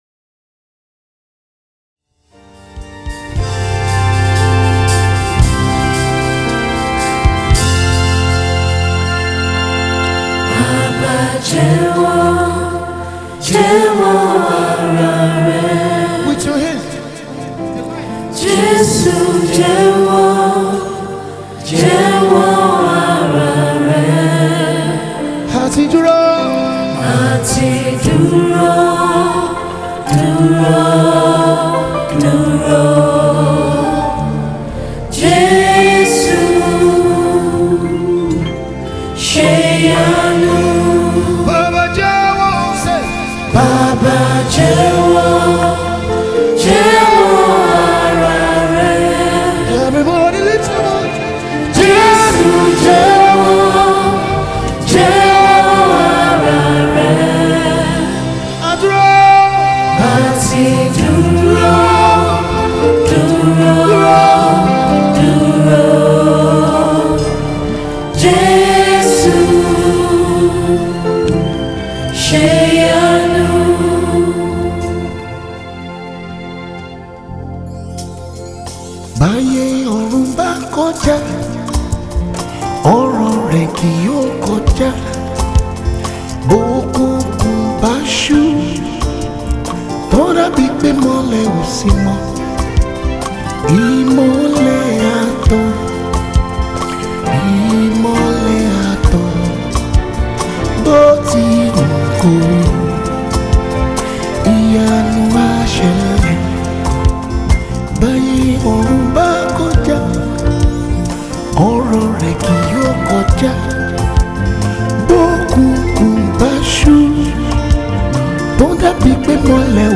Nigerian Gospel Musician
Live Recording concert